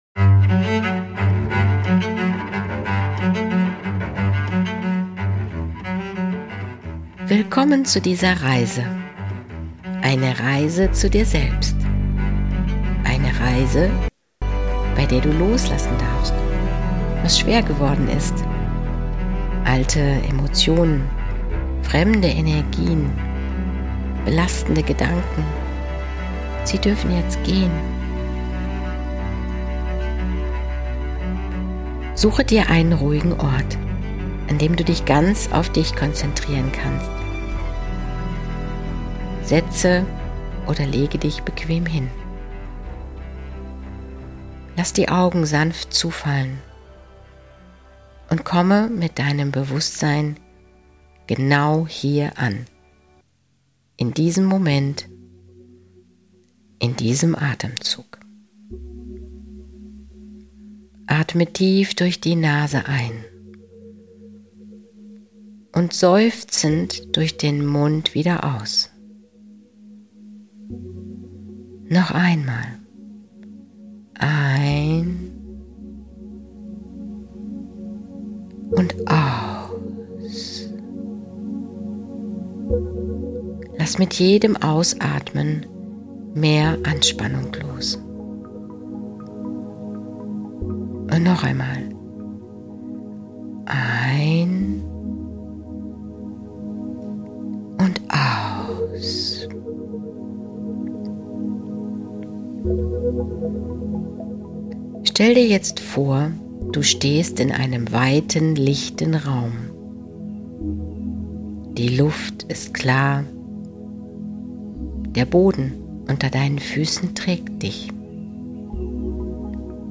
Nr. 61 - Geführte Meditation: Loslassen & Leichter werden - Deine heilende Reise für innere Freiheit & neue Energie